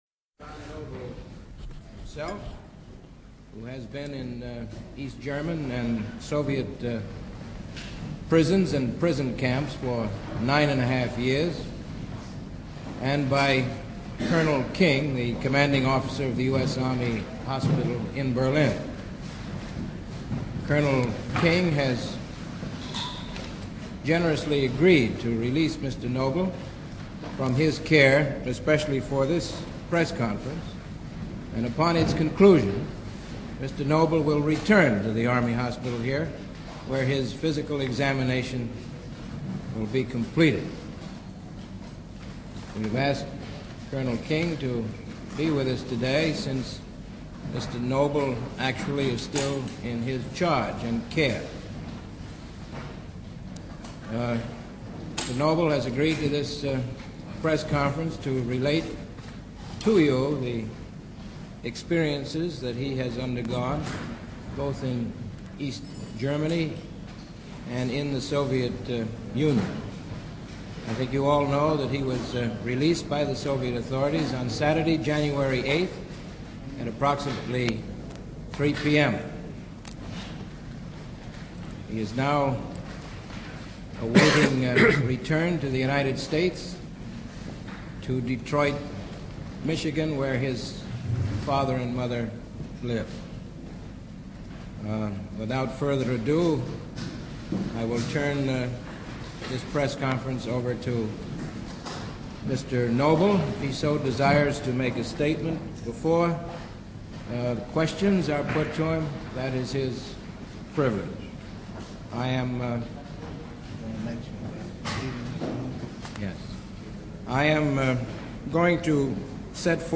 Pressekonferenz
Die Pressekonferenz vom 11.01.1955 im Orginalton (englisch)